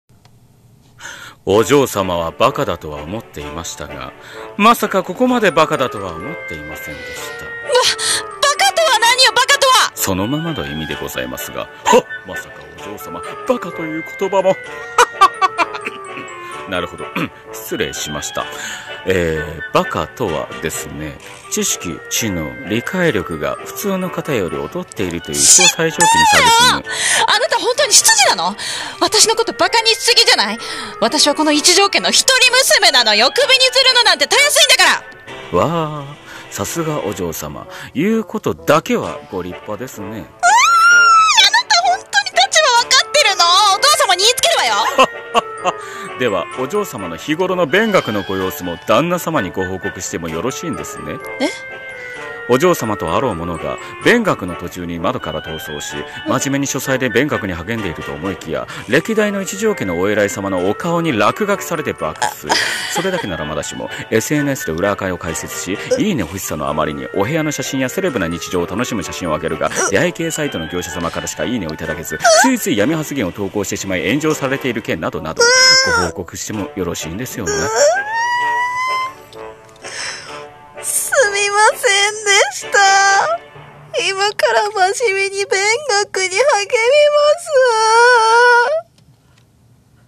バカなお嬢様【掛け合い】